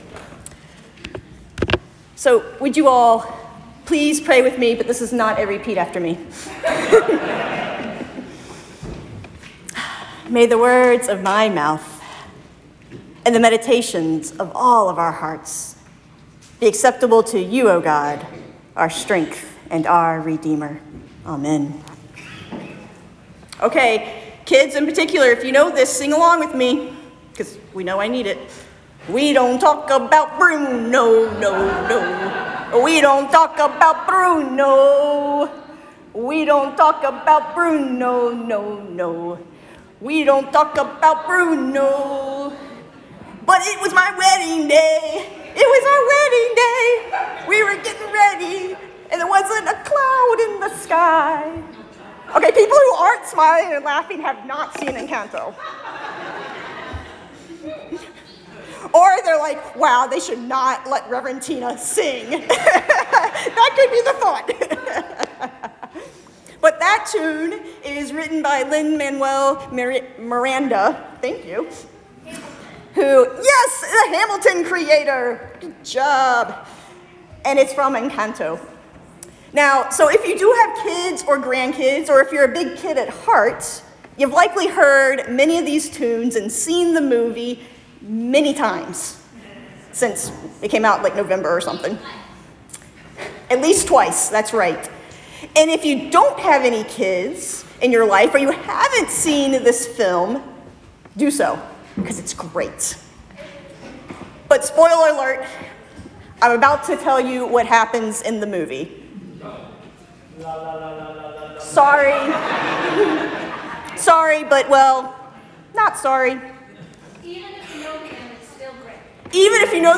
Easter Sermon